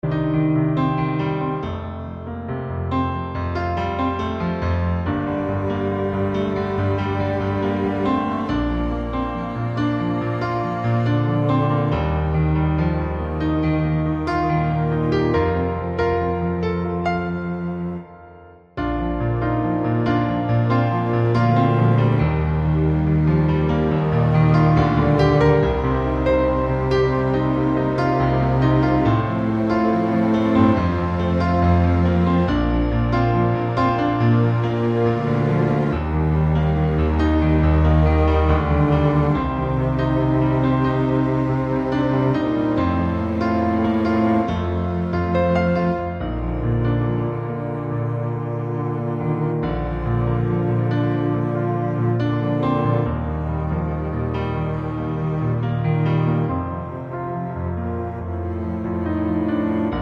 Two Semitones Down